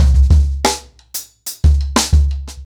Expositioning-90BPM.7.wav